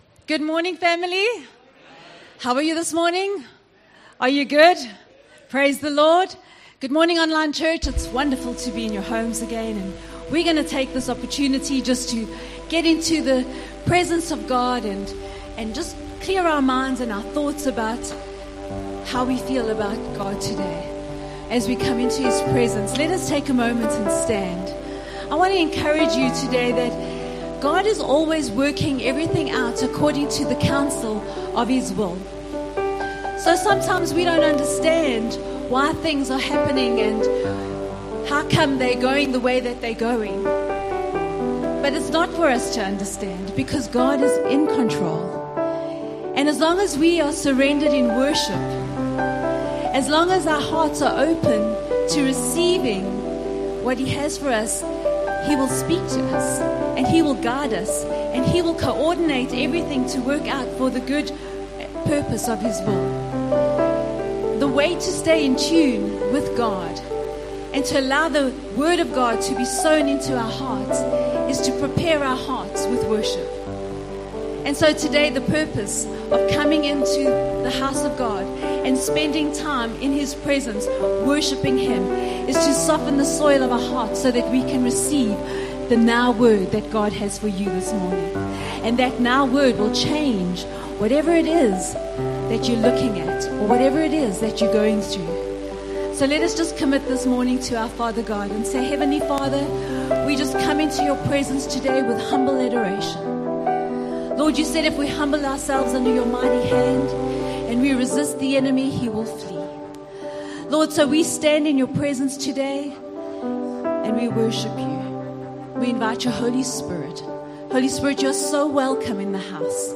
Ps-L-1st-Service.mp3